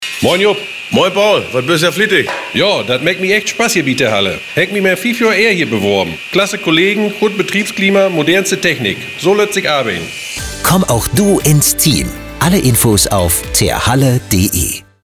Radiospot „Terhalle als Arbeitgeber plattdeutsch“
Radiospot-Terhalle-als-Arbeitgeber-plattdeutsch.mp3